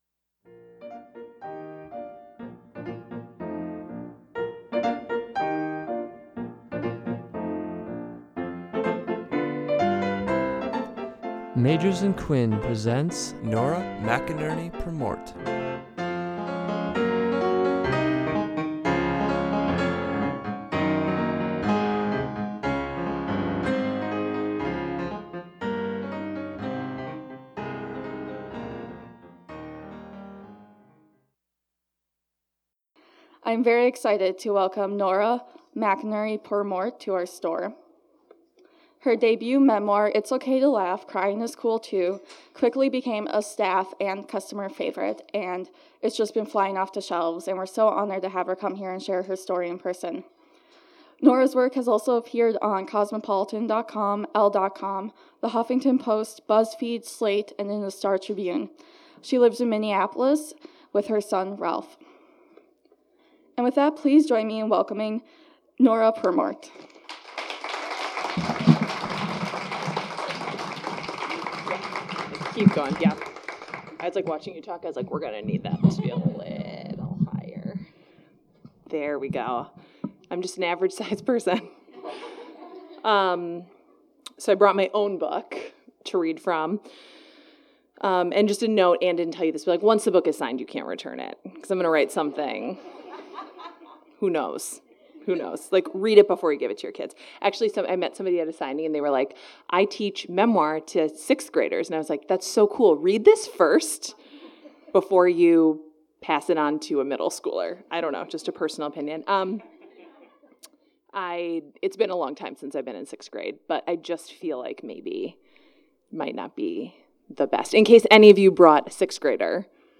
Nora McInerny Purmort visited the store in June to read from her heart-wrenching and hilarious (yeah, both) memoir, It's Okay to Laugh (Crying is Cool Too).